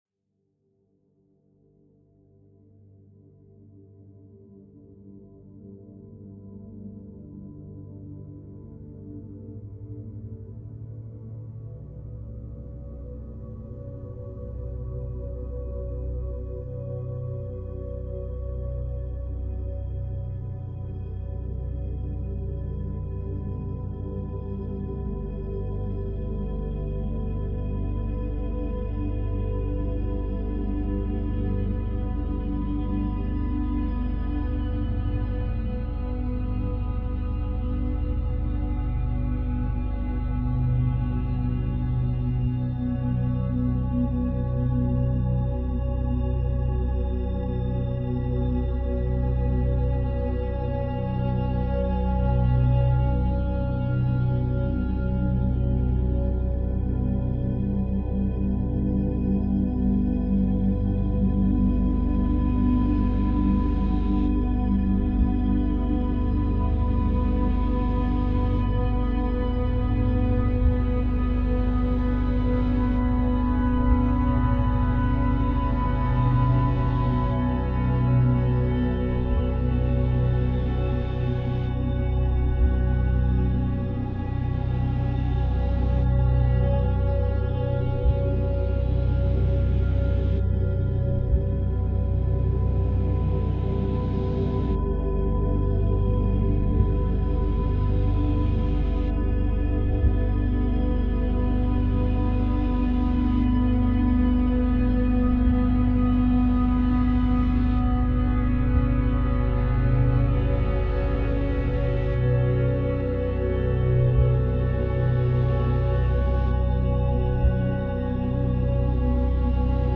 Speed 110%